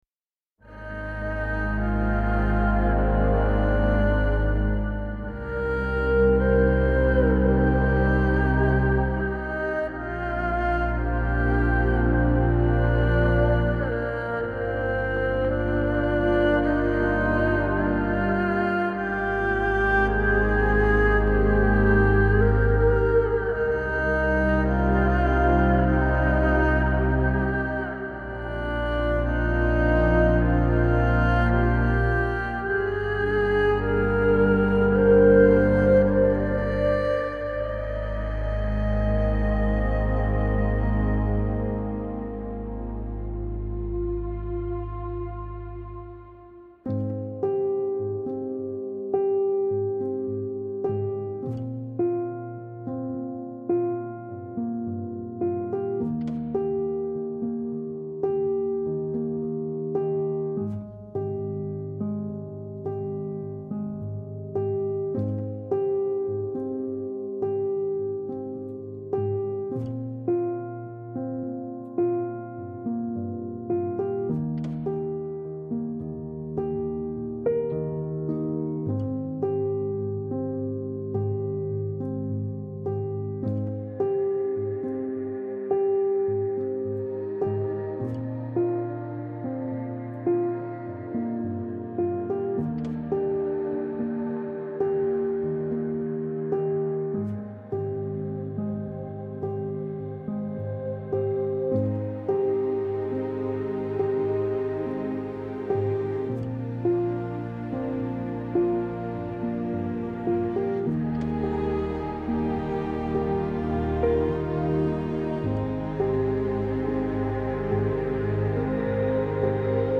آرامش بخش الهام‌بخش پیانو عصر جدید موسیقی بی کلام